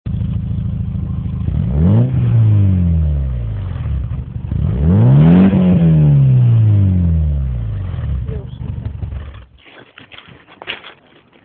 dzwięk wydechu
A nagrałem dźwięk wydechu.
wydech.mp3